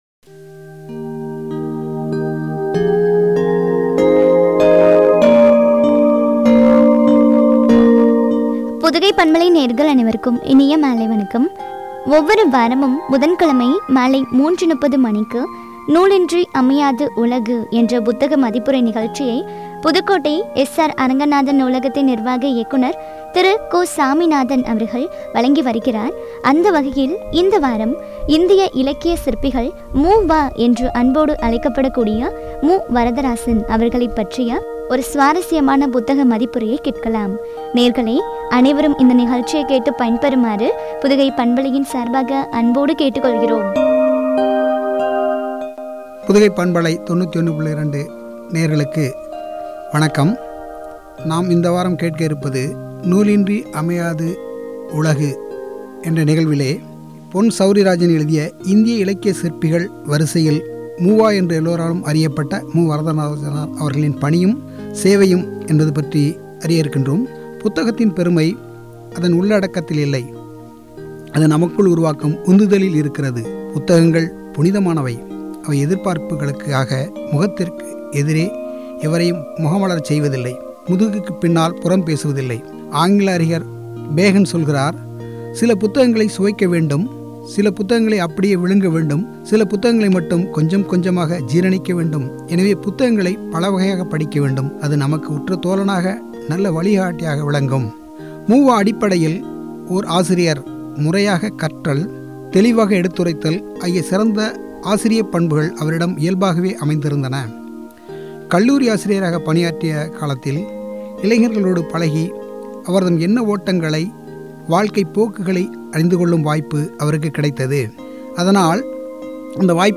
இந்திய இலக்கிய சிற்பிகள் மு. வ (மு. வரதராசன்) புத்தக மதிப்புரை (பகுதி -07)